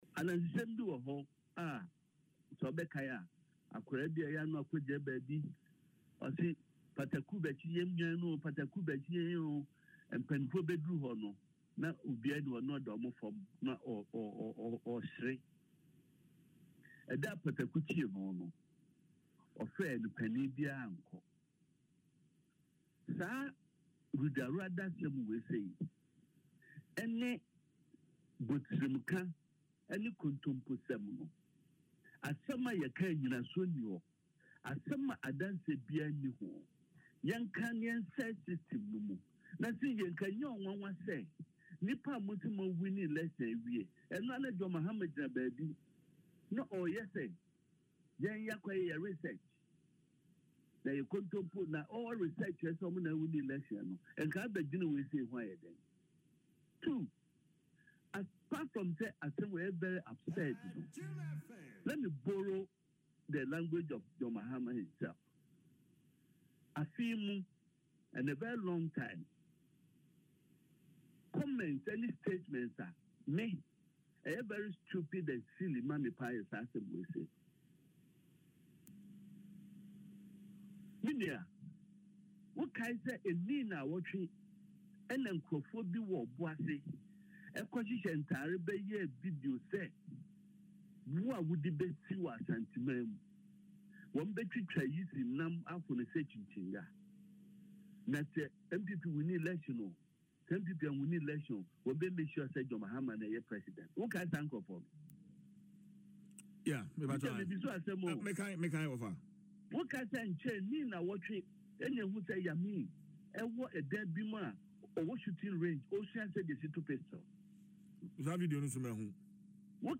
Reacting to this, Mr. Pyne, in an interview on Adom FM’s Dwaso Nsem, labeled the allegations as absurd.
Sam-Pyne-on-election.mp3